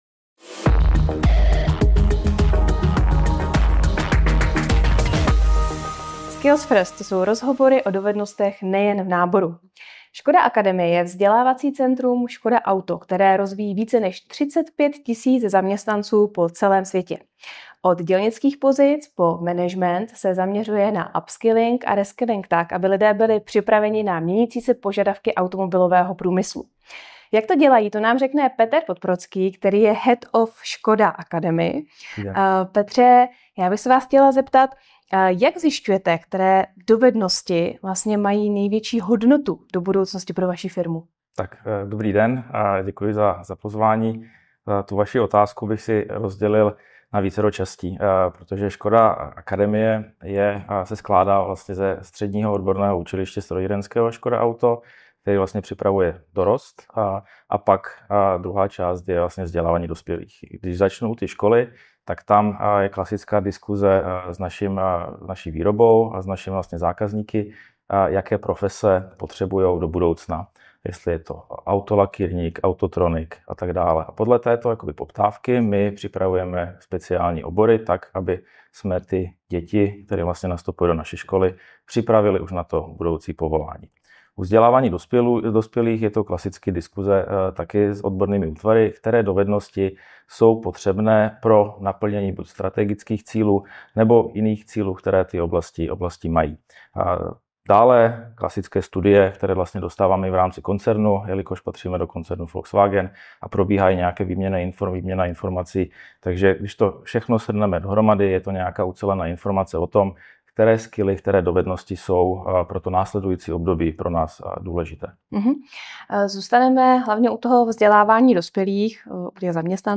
Celý rozhovor